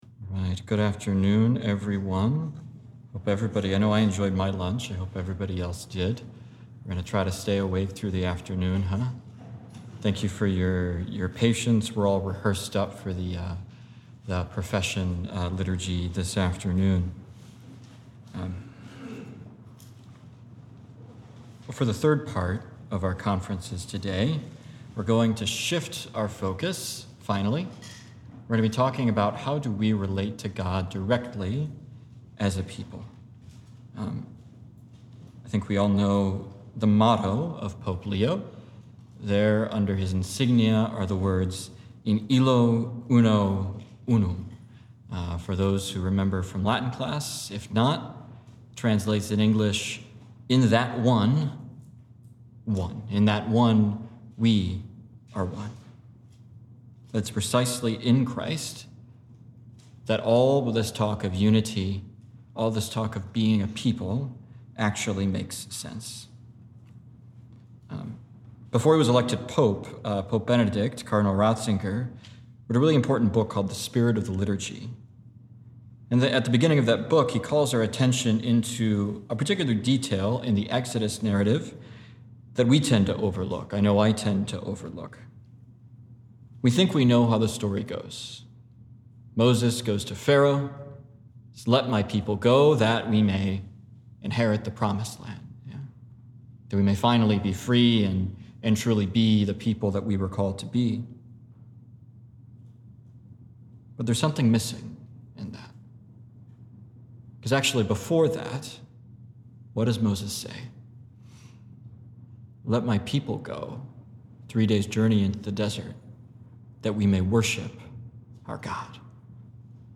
2025 Day of Recollection – Conference 3